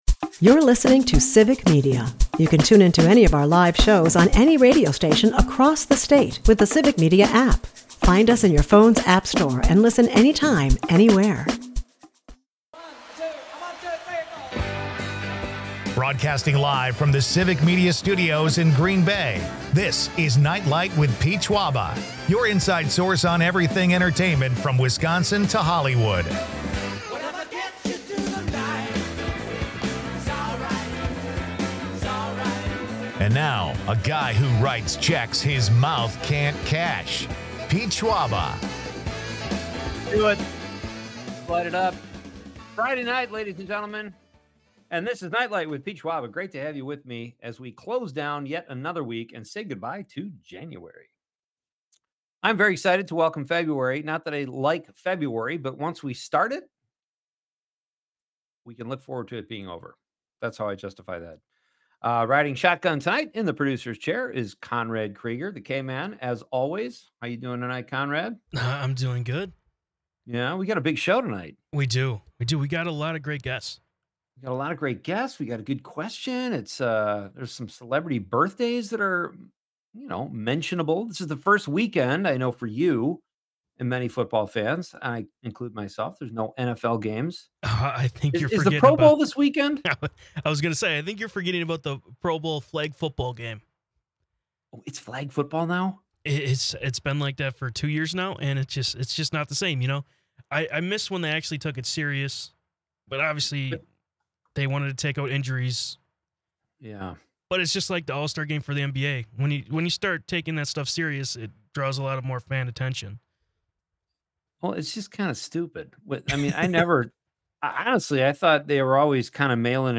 Leave the stress of the day behind with entertainment news, comedy and quirky Wisconsin.